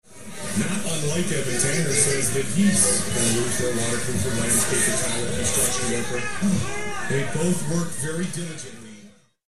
He’s kind of the play by play guy of the UFC fights along side Joe Rogan.
It’s tough to tell 100%, but very robotic: